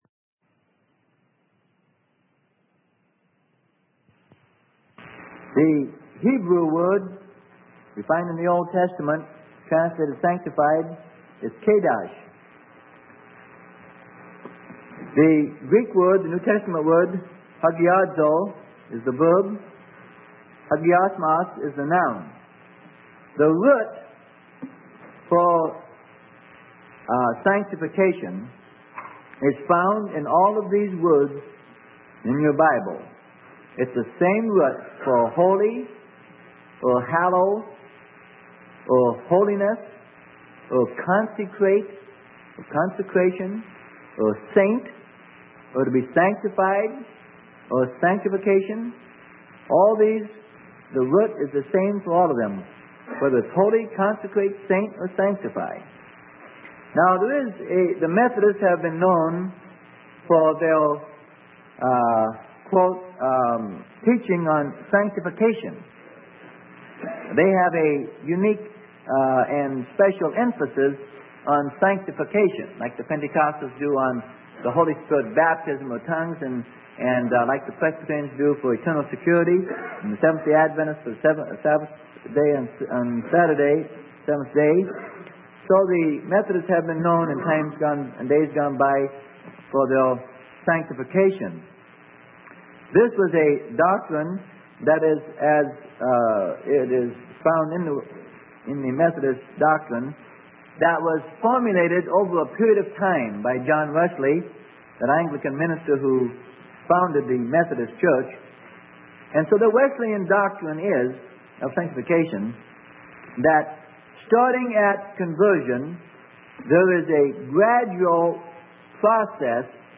Sermon: Sanctification - Part 1 - Freely Given Online Library